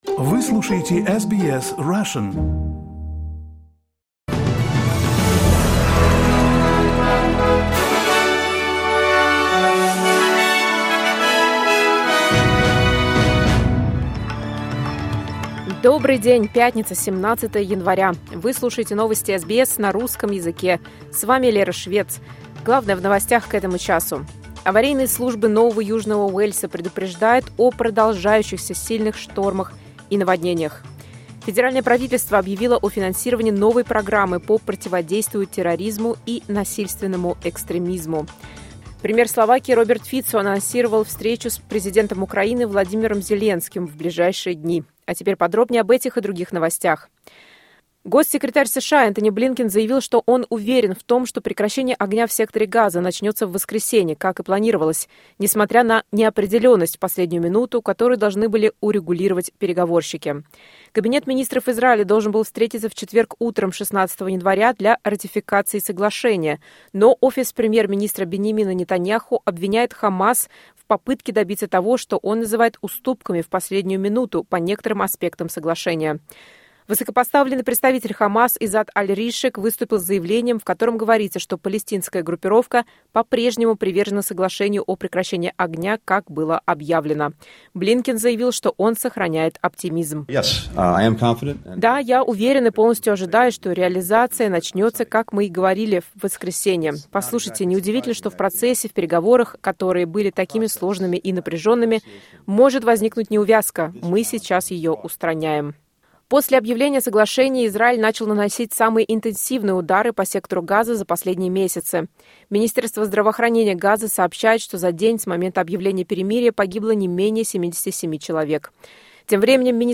Новости SBS на русском языке — 17.01.2025